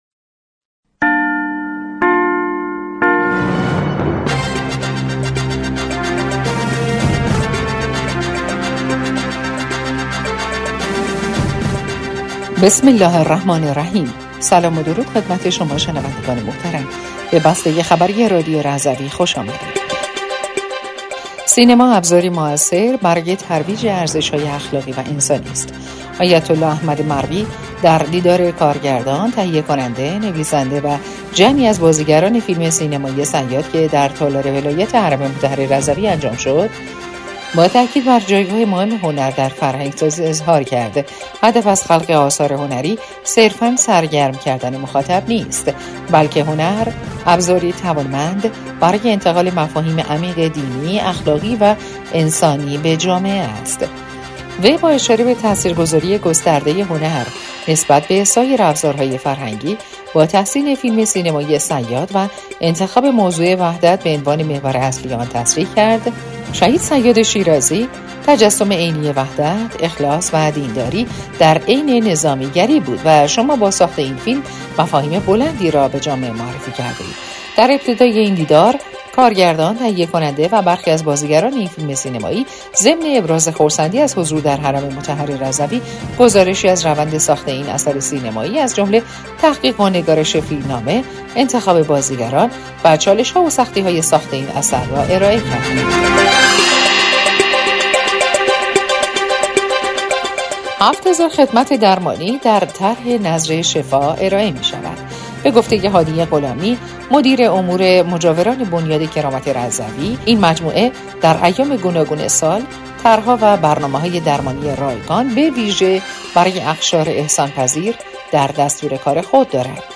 بسته خبری رادیو رضوی شنبه ۶ اردیبهشت؛